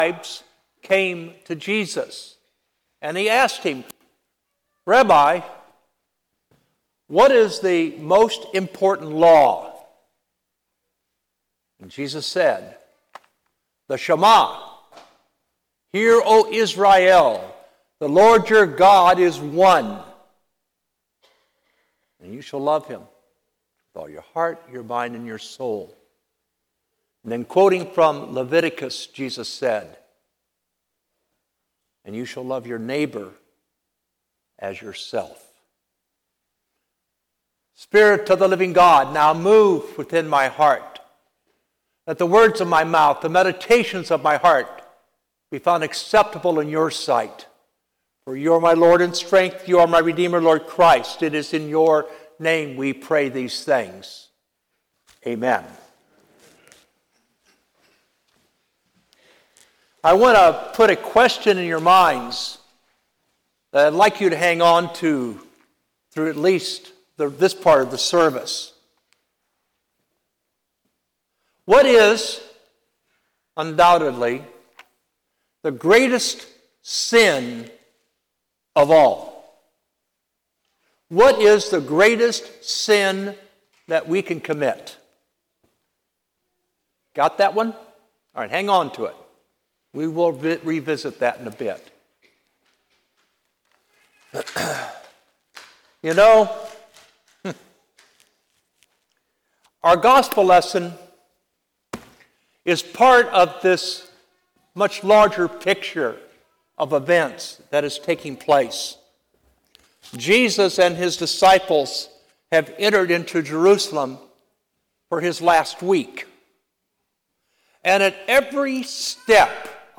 Sermon 11/03/24 Twenty-Fourth Sunday after Pentecost (All Saints) - Holy Innocents' Episcopal Church